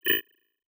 Holographic UI Sounds 102.wav